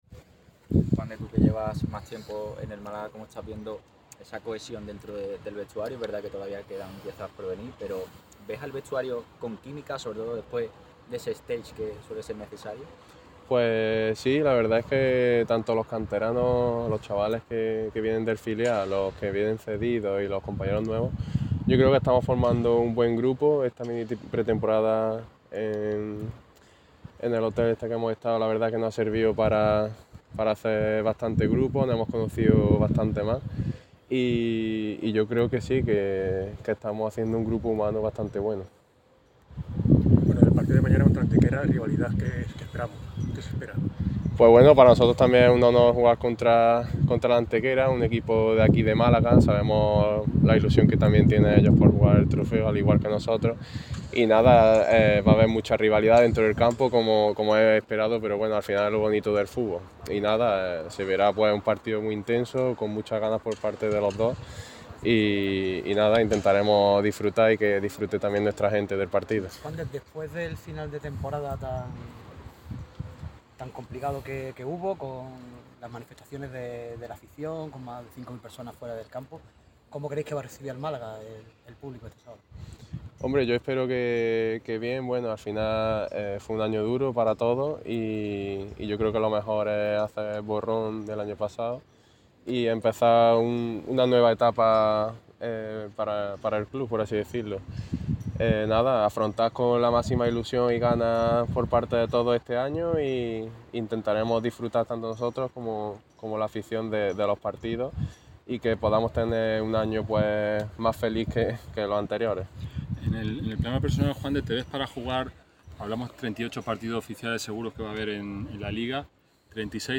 Este viernes se ha presentado ante los medios el XXXIV Trofeo Costa del Sol. Ha tenido lugar en el césped de La Rosaleda, donde han hablado Dioni Villalba y Juande Rivas como representantes de la plantilla malaguista.
Palabras de Juande Rivas